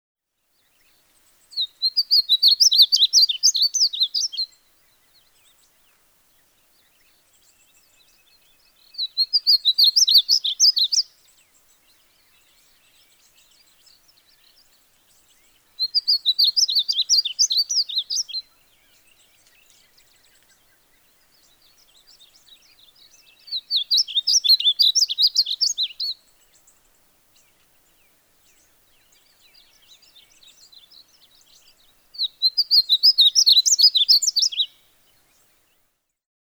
Painted Bunting: Male | Hunterdon Art Museum
bunting-song.mp3